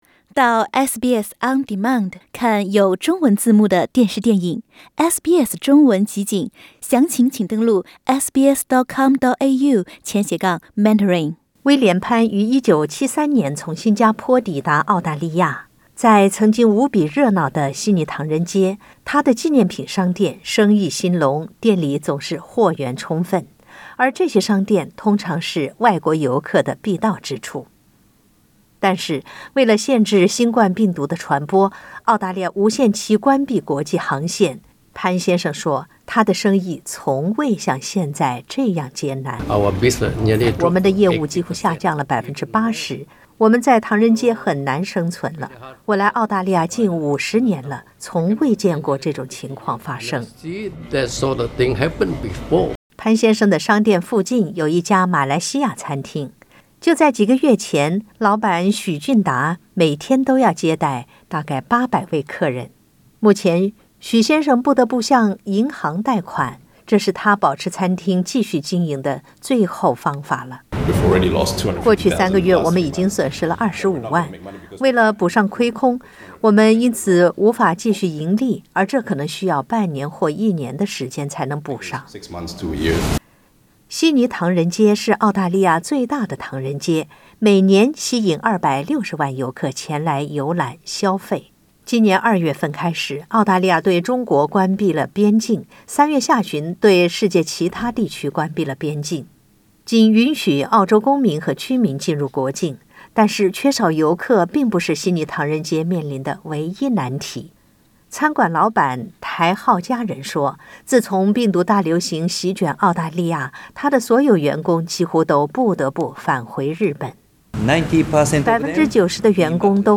受新冠病毒大流行打击，悉尼唐人街大部分店铺关门，出现近50年来最萧条景象，空荡如同“鬼城”。（点击上图收听录音报道）